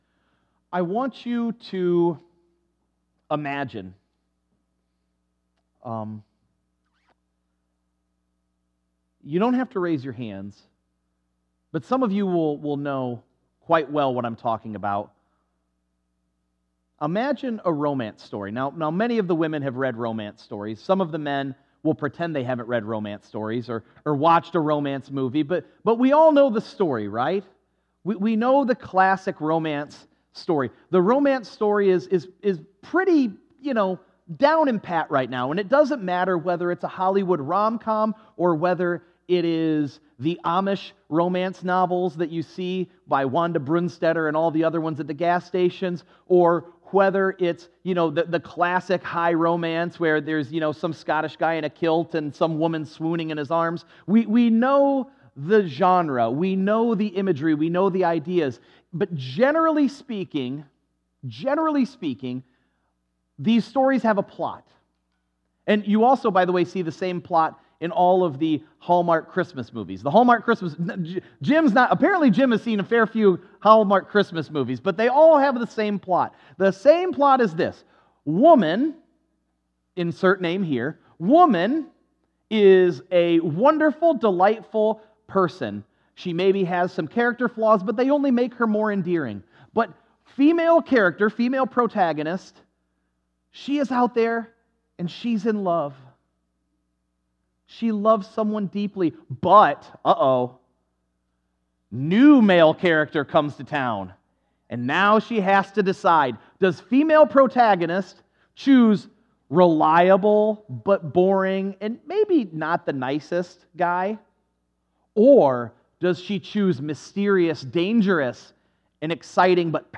Sermons - First Brethren Church- Bryan Ohio